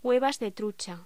Locución: Huevas de trucha